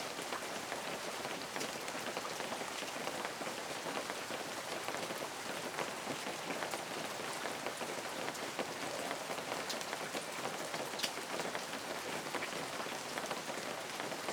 rain_light.ogg